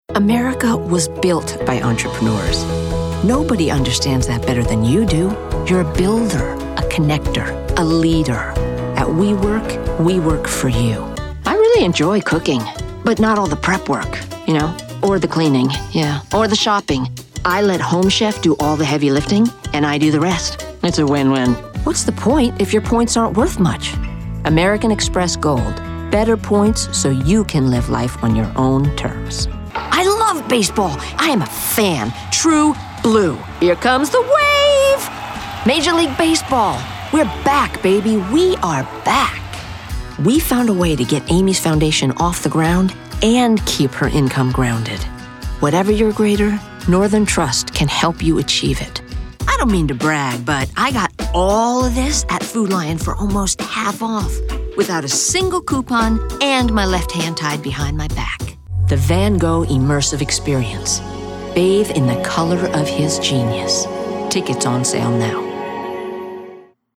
For commercial, documentary, or narrative projects seeking both authority and authenticity, my voice is versatile, warm, and compelling.
Commercial